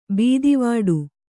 ♪ bīdivaḍu